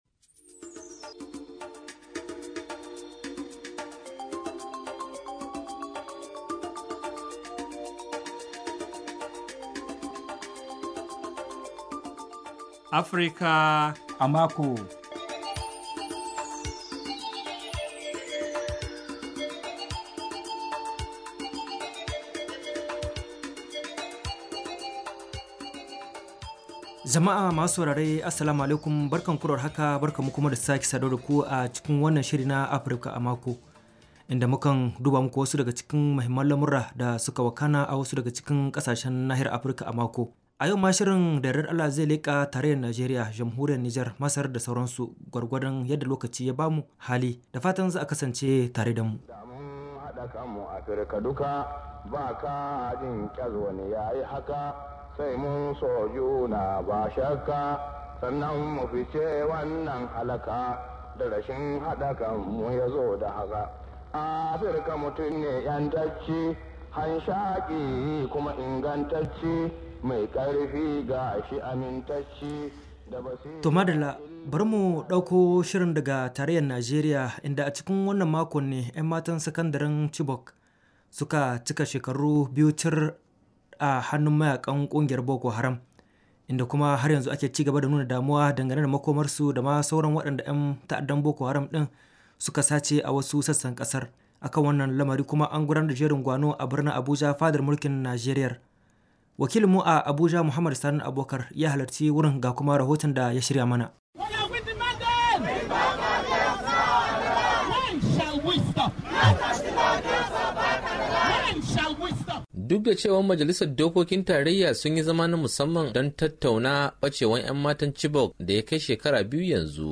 To a nasu bangaren majalisun dattijai da na wakilai a Najeriya sun gudanar da zama kan wannan batu, Sanata Gobir dan majalisar dattijai ne, ya yi wa sashen Hausa Karin bayani kan zaman nasu: …………………..